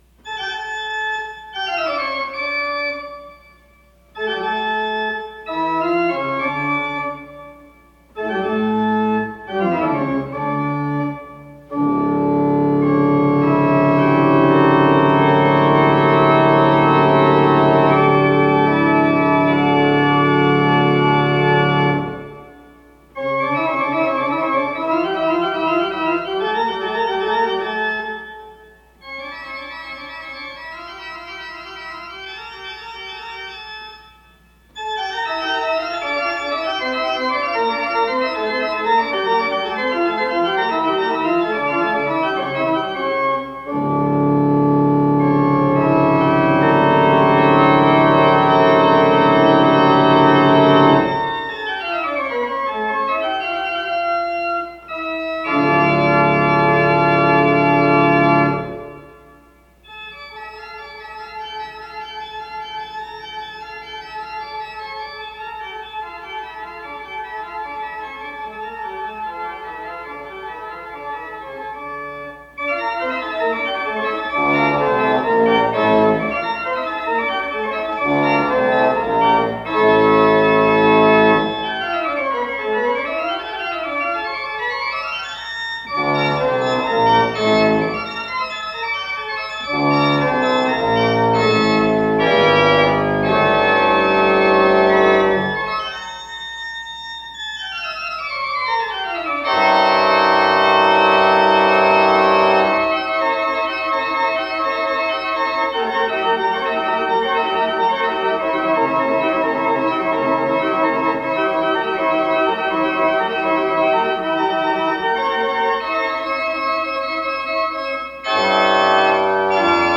Bach - Toccata und Fugue in D minor BWV 656